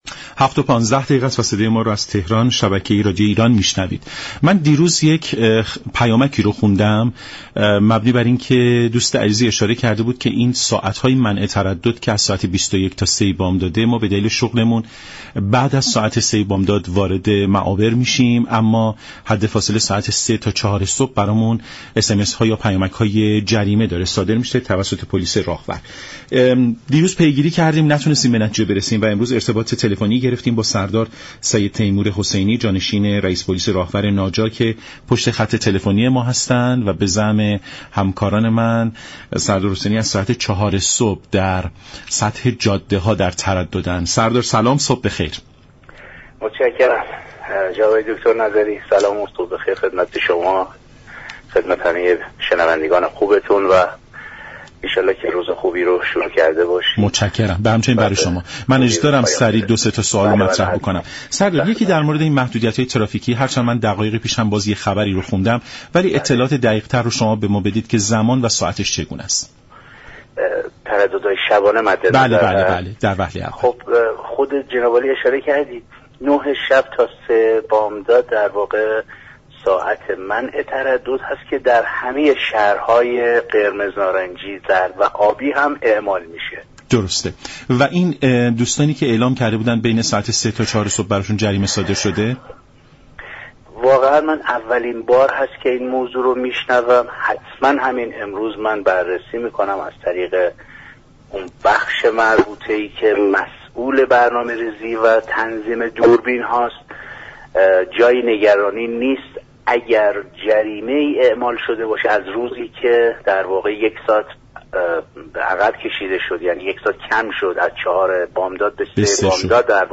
به گزارش شبكه رادیویی ایران، سردار سید تیمور حسینی جانشین رییس پلیس راهور ناجا در برنامه سلام صبح بخیر رادیو ایران از اعمال محدویت ها در ترددهای شبانه خبر داد و گفت: منع ترددهای شبانه كه در تمامی شهرهای قرمز، نارنجی، زرد و آبی اعمال می شود از ساعت 9:00 تا 3:00 بامداد است.